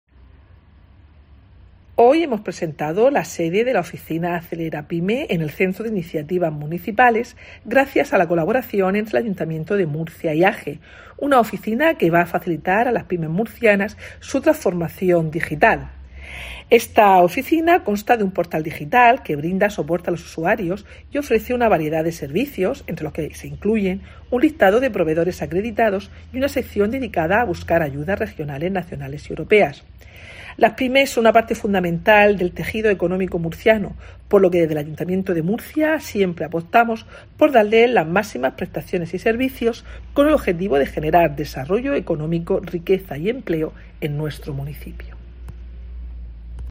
Mercedes Bernabé, concejal de Gobierno Abierto, Promoción Económica y Empleo